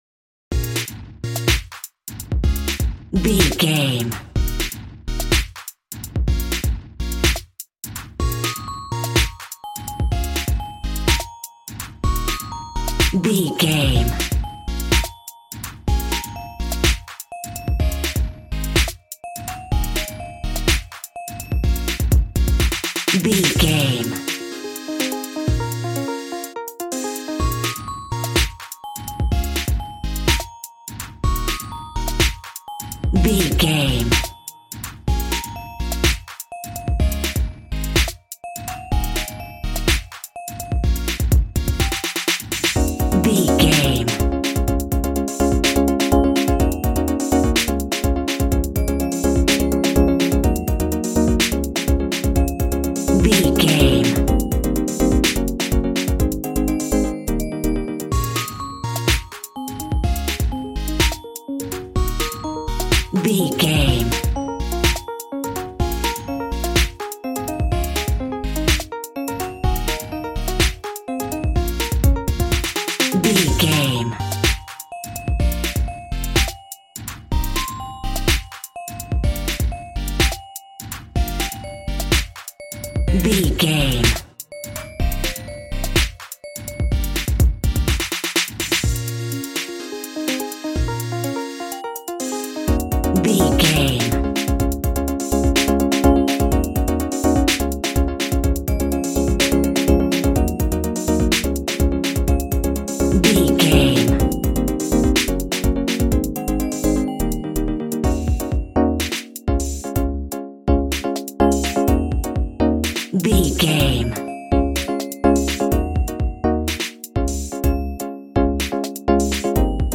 Aeolian/Minor
B♭
calm
smooth
synthesiser
piano